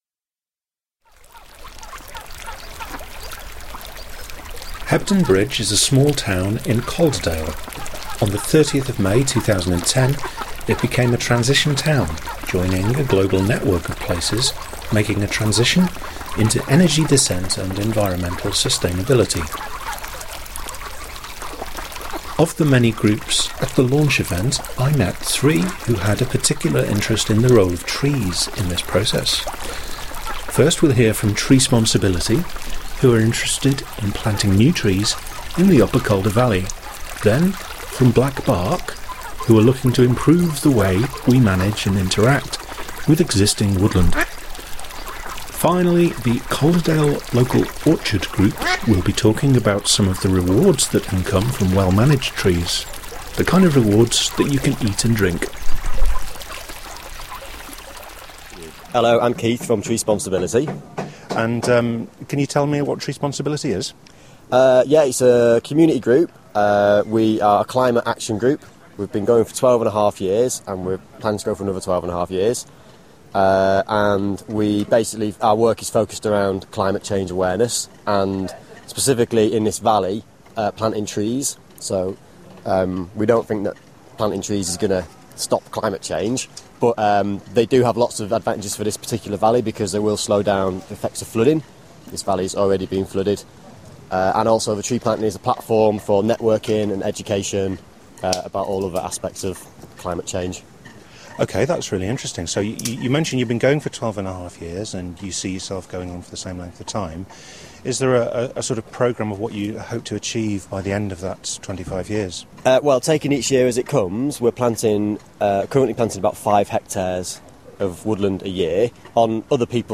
On 30th May 2010, Hebden Bridge launched as a Transition Town.
For this 15-minute package, I interviewed 3 of the groups working with trees in and around Hebden Bridge.